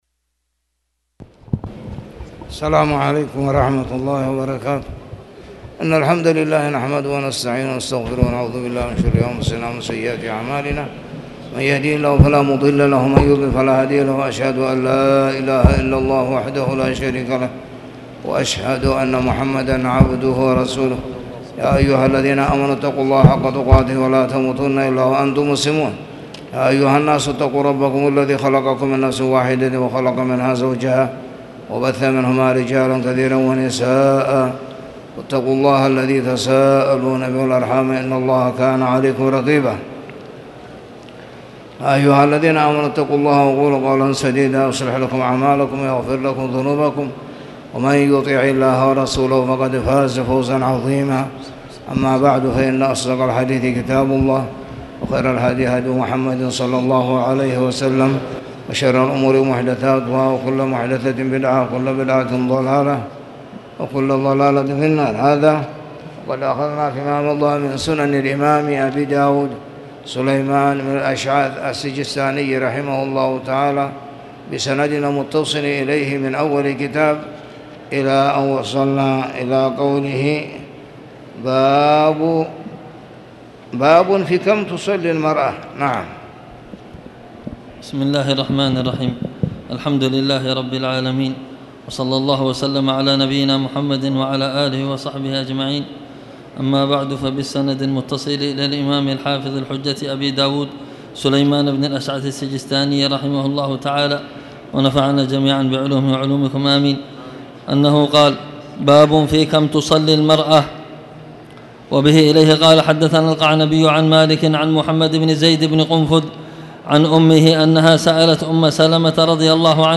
تاريخ النشر ١٧ محرم ١٤٣٨ هـ المكان: المسجد الحرام الشيخ